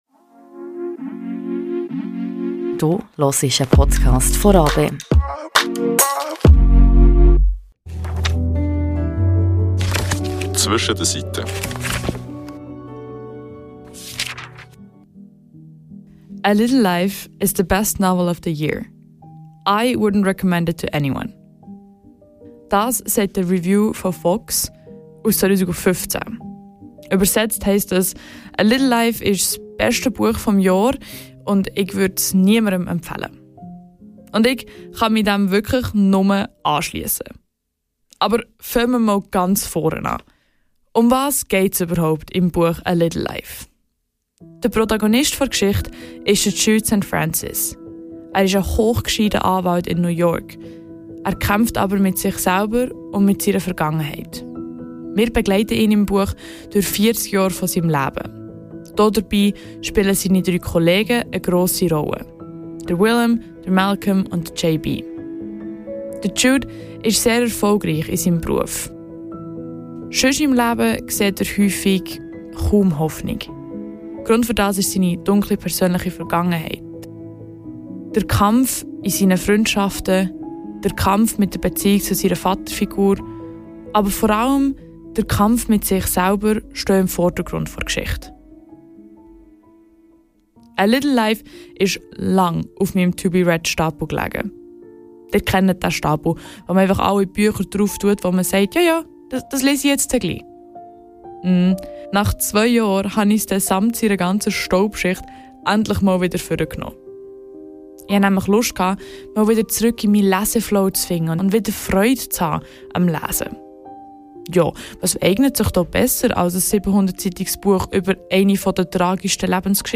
Die Oberaargauer Alt-Rock Band im Interview
kleines Unplugged-Konzert.